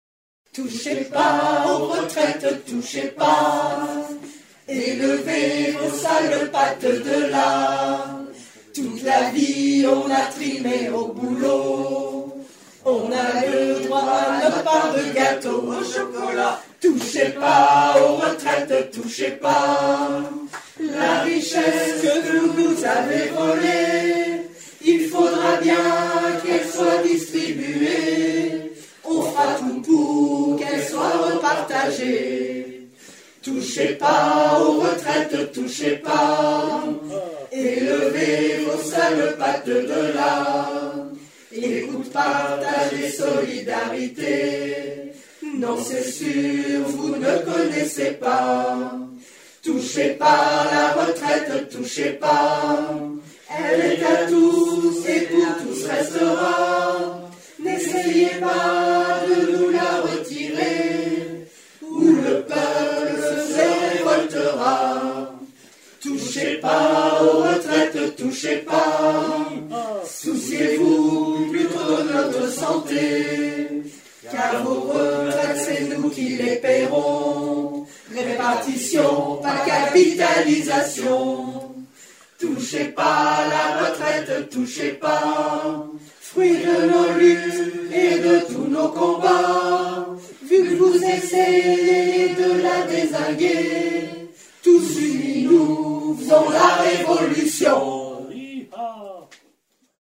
Fichier audio : y’a juste les refrains… en attendant une version complète !
Les glottes rebelles parcourent un répertoire de chansons de luttes : chants révolutionnaires historiques, chansons de tous pays contre les dictatures, les guerres et les injustices, chansons inspirées des luttes ouvrières et de l'actualité sociale et politique d'aujourd'hui...